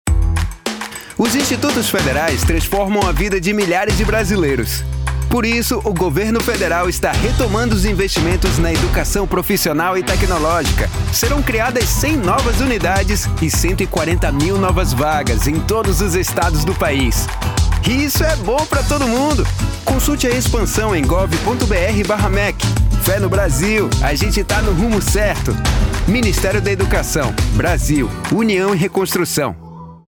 Spot - Campanha Fé no Brasil - Centro-Oeste - DF